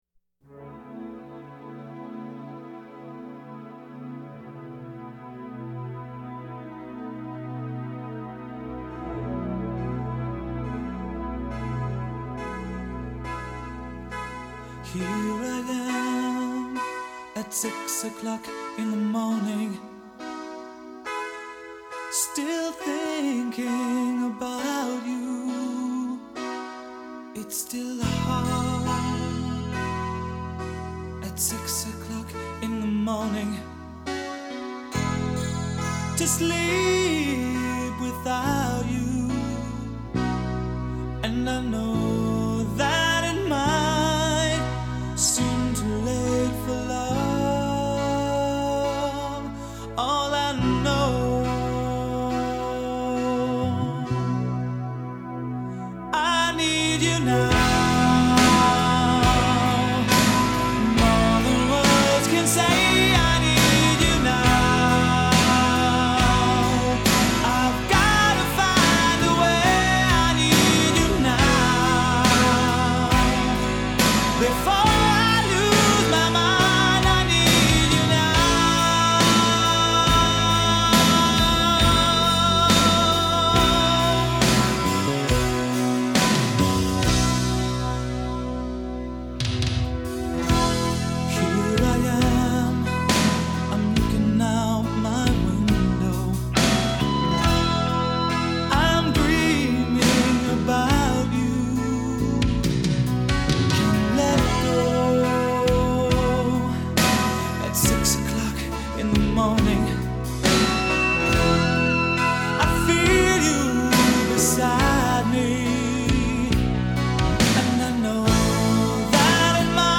all of those helium voiced power ballads